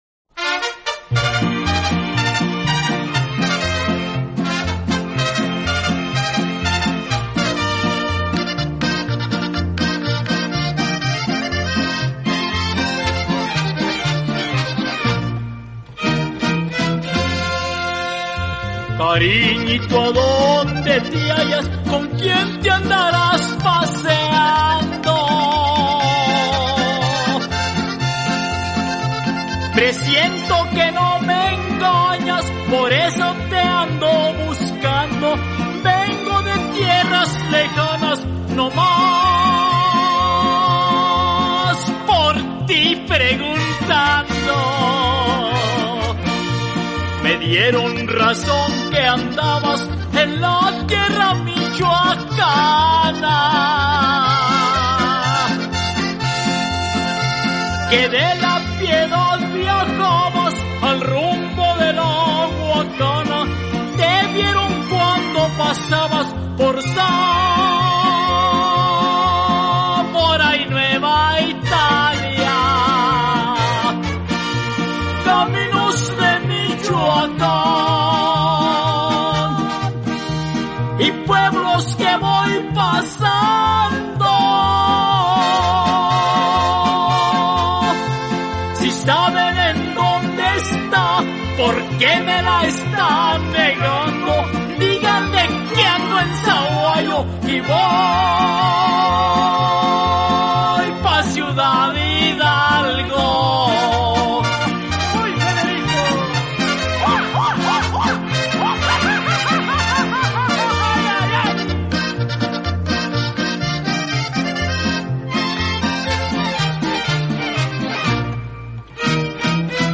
an old ranchera song